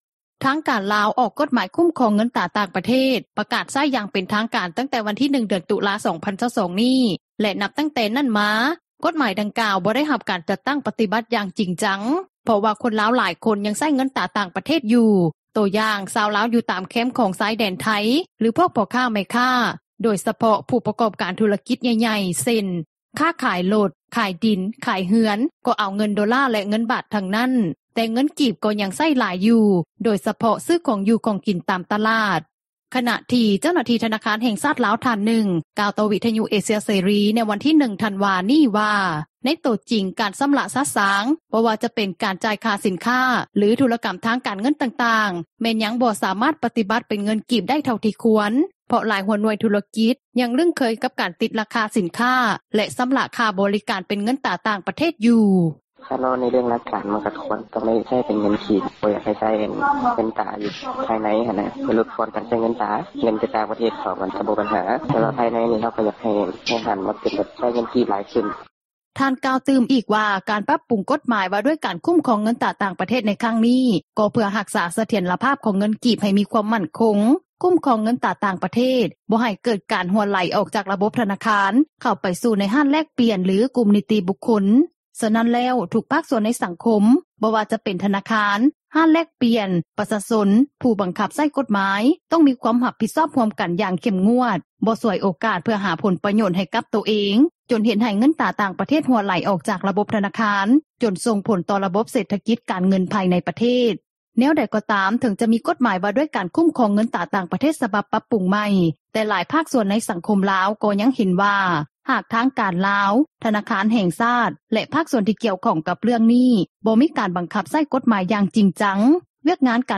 ດັ່ງແມ່ຄ້າຜູ້ນີ້ ກ່າວຕໍ່ວິທຍຸ ເອເຊັຽ ເສຣີ ໃນມື້ດຽວກັນນີ້ວ່າ:
ດັ່ງຊາວລາວຜູ້ນີ້ ກ່າວຕໍ່ວິທຍຸເອເຊັຽເສຣີ ໃນມື້ດຽວກັນນີ້ວ່າ: